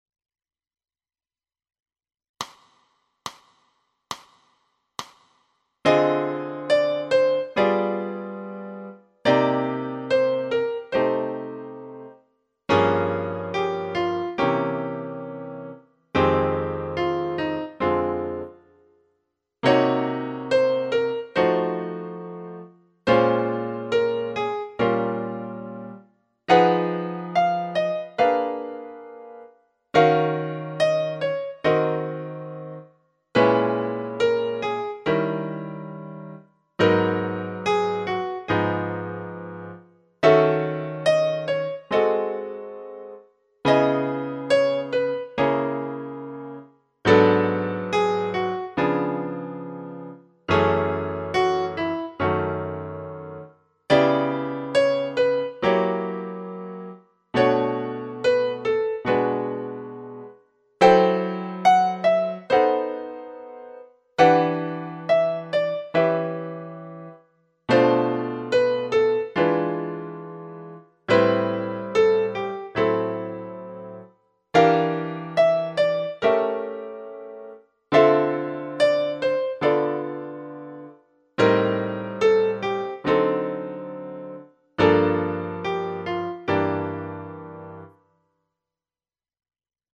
Faixa 32 – Distribuição Um Aberta do Modo Menor com Melodia